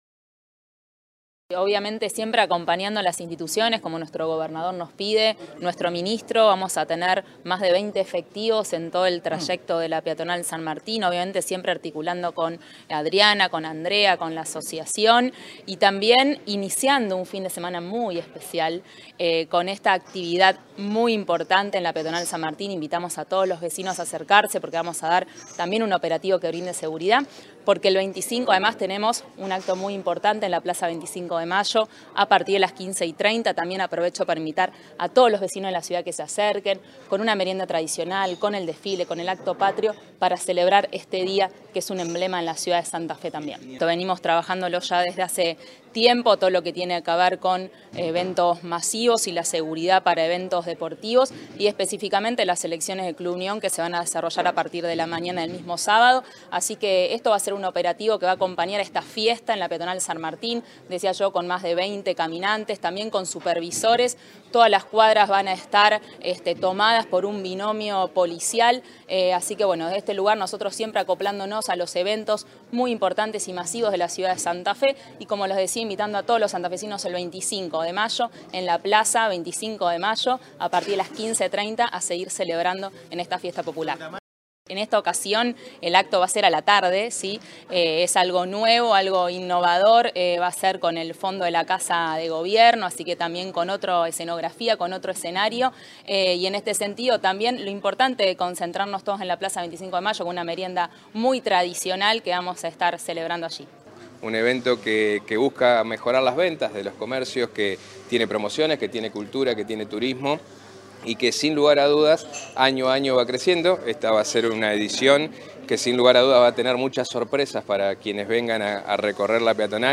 Funcionarios provinciales estuvieron presentes este viernes en una conferencia de prensa en el Gran Doria Bar donde se presentó la Fiesta de la Peatonal San Martín en la ciudad de Santa Fe a desarrollarse este sábado 24.
Declaraciones de Coudannes